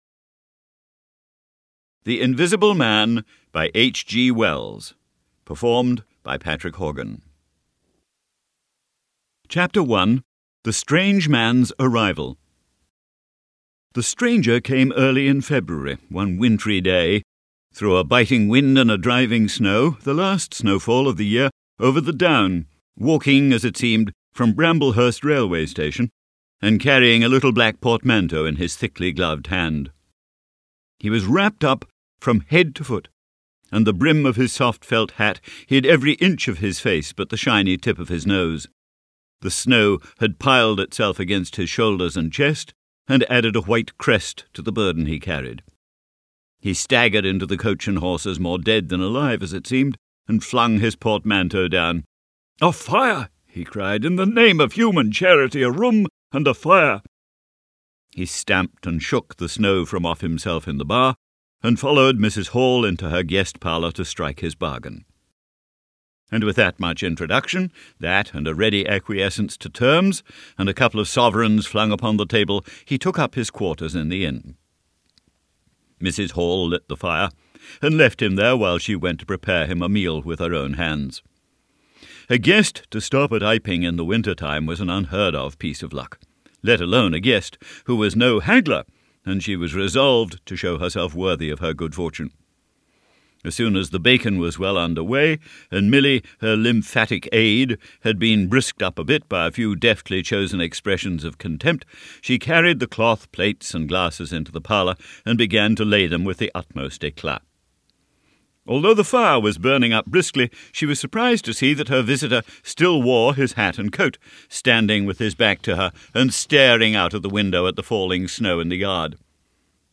The Invisible Man by H. G. Wells, unabridged audiobook mp3 d/l